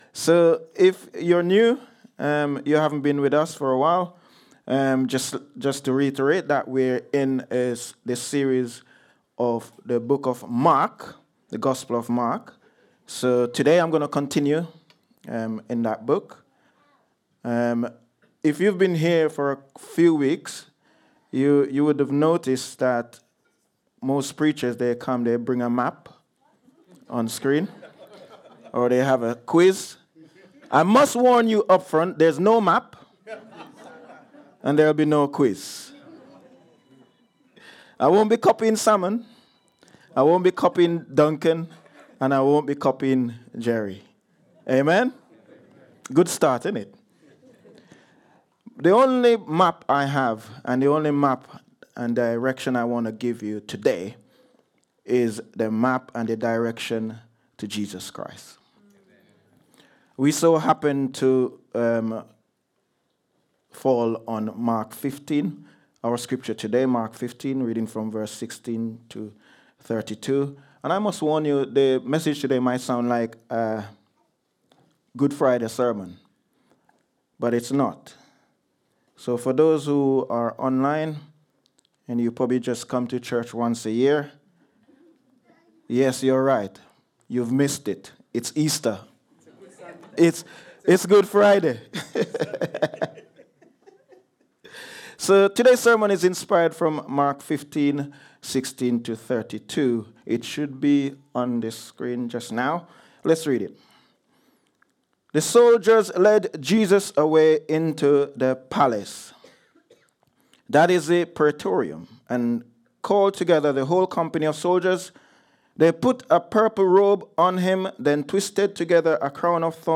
Download Jesus and the Crucifixion | Sermons at Trinity Church